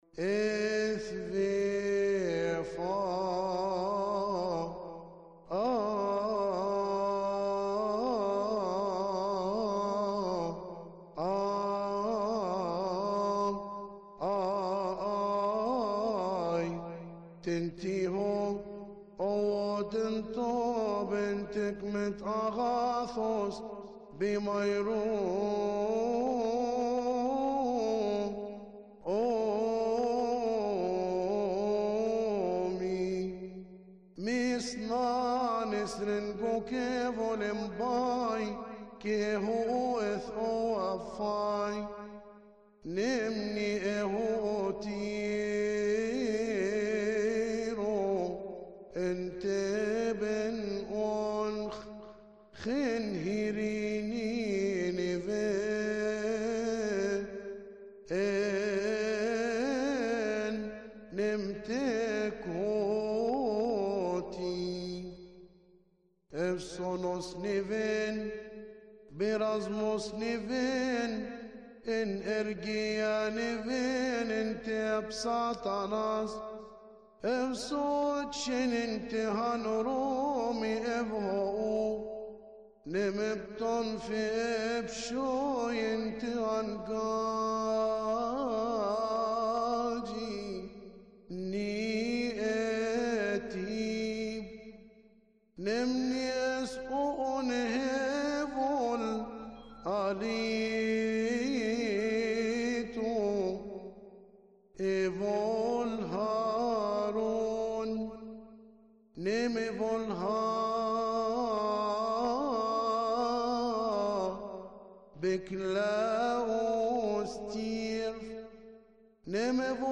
مكتبة الألحان
للكاهن